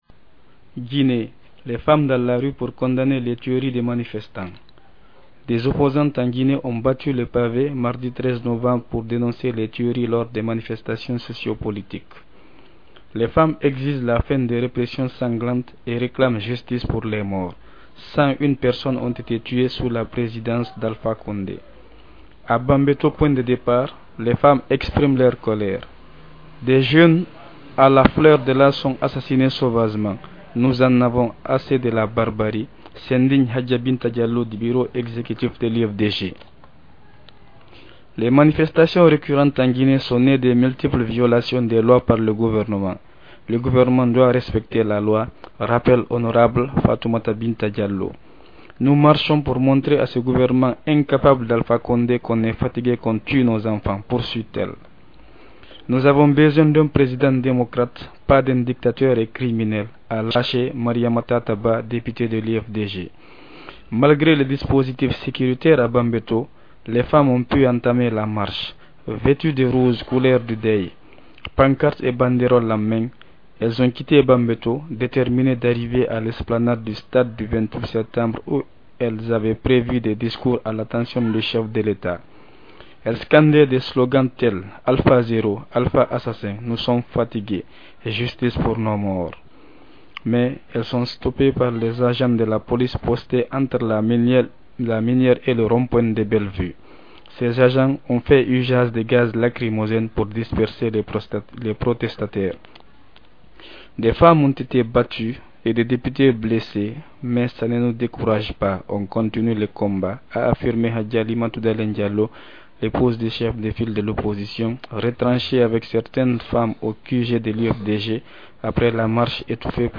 A Bambeto, point de départ, les femmes expriment leur colère.
Elles scandaient des slogans tels: "Alph zéro", "Alpha assassin", "nous sommes fatiguées", "justice pour nos morts" .